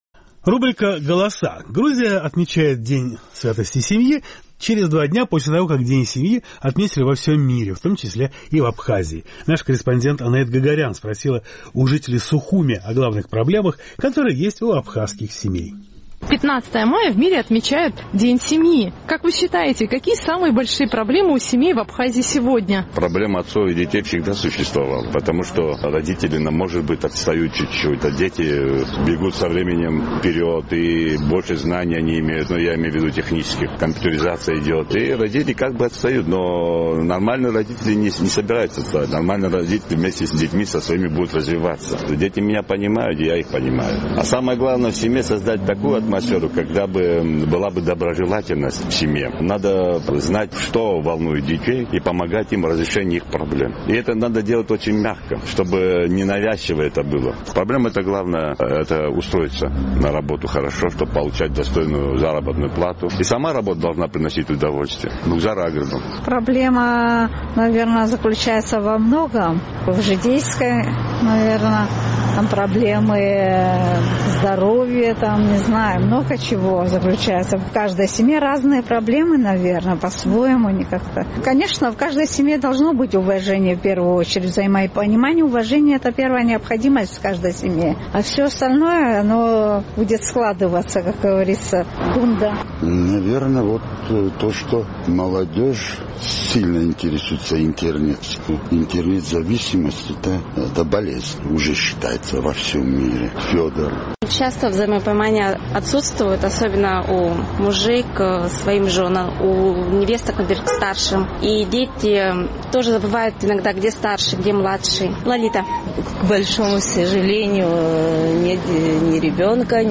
15 мая в мире праздновали как День семьи. Наш сухумский корреспондент поинтересовалась у местных жителей, каковы самые большие проблемы у абхазских семей.